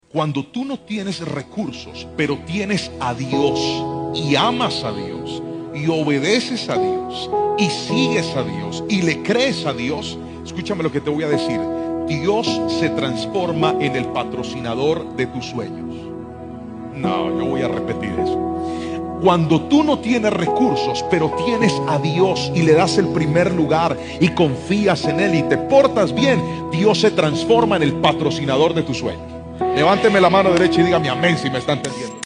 Predicador